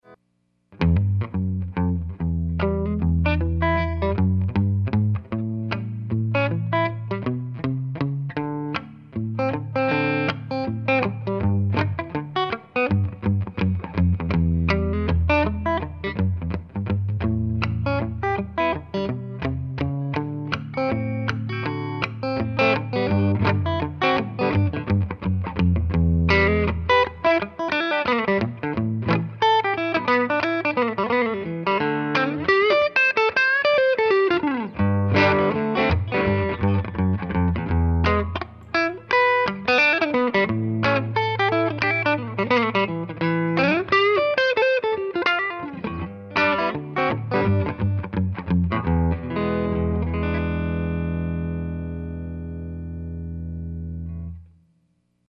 REC: Rode NT1 and a Sure sm57 into Cubase | No effects added.